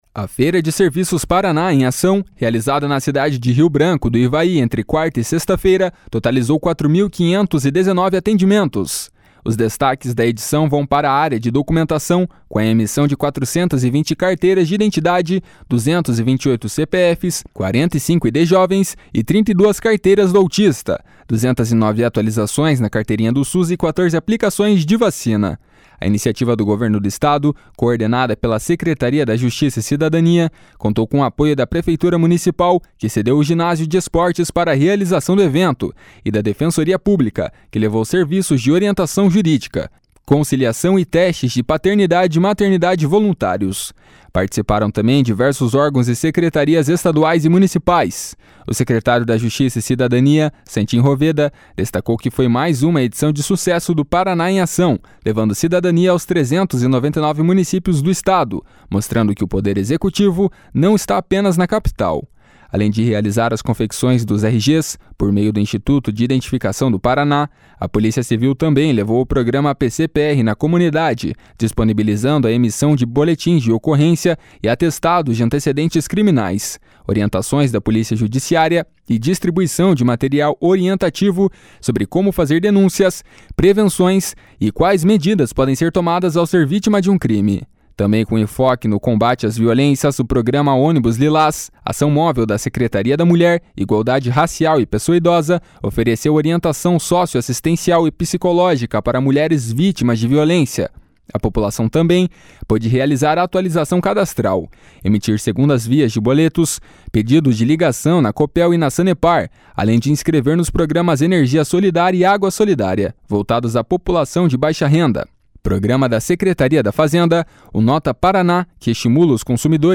O secretário da Justiça e Cidadania, Santin Roveda, destacou que foi mais uma edição de sucesso do Paraná em Ação, levando cidadania aos 399 municípios do Estado, mostrando que o Poder Executivo não está apenas na capital.